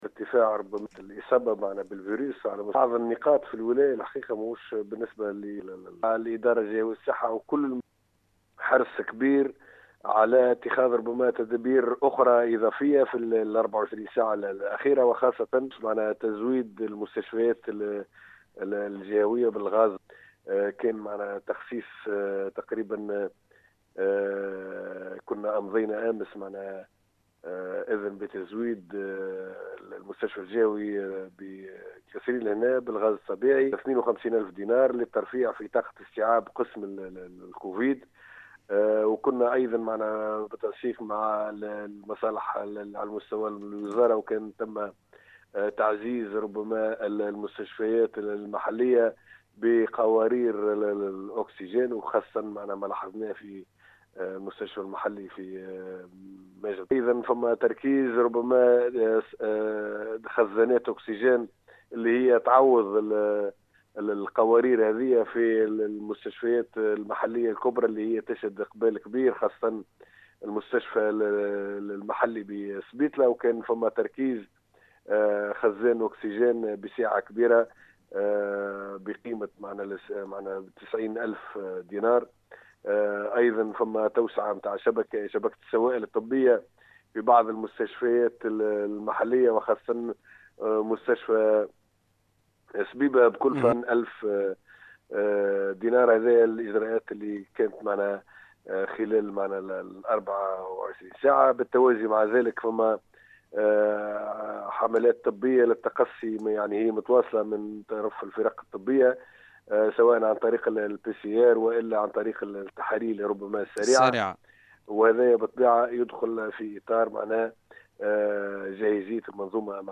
افاد والي الجهة عادل المبروك للسيليوم اف ام ان اللجنة الجهوية لمجابهة كرونا اتخدت ت عديد الإجراءات بالتنسيق مع الادارة الجهوية للصحة  لمجابهة خطر توسع دائرة العدوى بالجهة  وذلك من خلال تزويد المستشفى الجهوي بالقصرين  والمستشفيات المحلية الكبرى بالأكسجين الطبيعي وتركيز خزانات أكسجين على غرار المستشفى المحلي بسبيطلة  والمستشفى المحلي بسبيبةمؤكدا على تواصل حملات التقصي كما تم تم رصد اعتمادات اضافية في ميزانية المجلس الجهوي لسنة 2021
الوالي-1.mp3